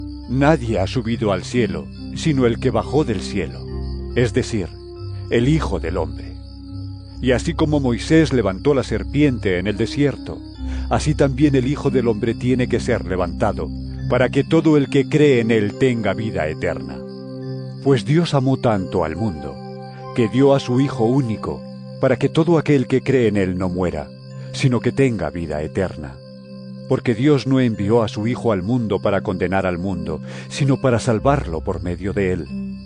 Jn 3 13-17 EVANGELIO EN AUDIO